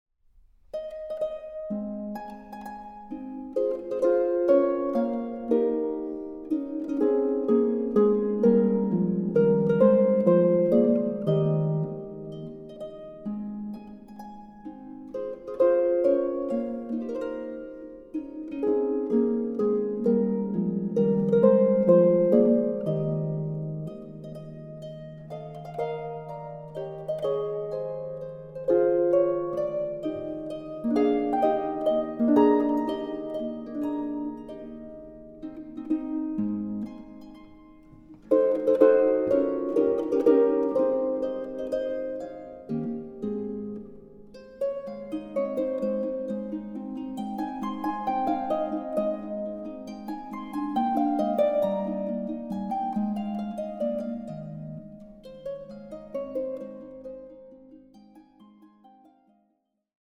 Harp
Recording: Festeburgkirche Frankfurt, 2024